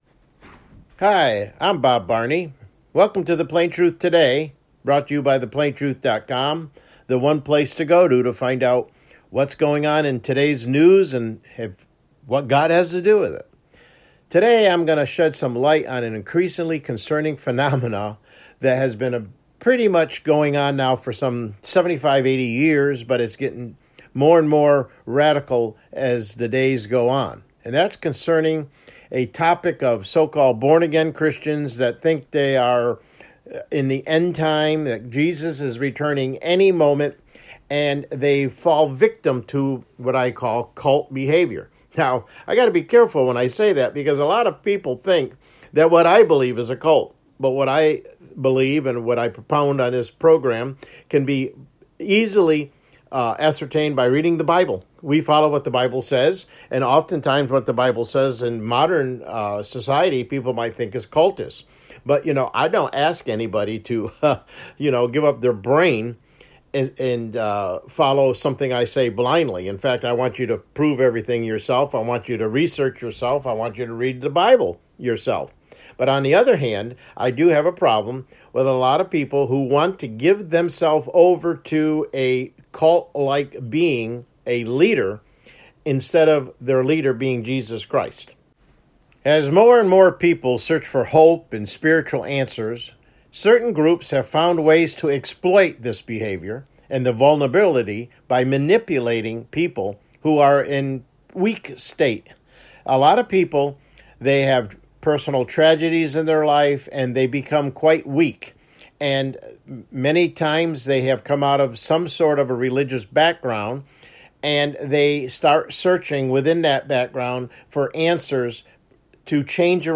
CLICK HERE TO LISTEN TO THE PLAIN TRUTH TODAY MIDDAY BROADCAST: Follow Me AS I Follow Christ | Put Your Eternal Life in No Man’s Hand!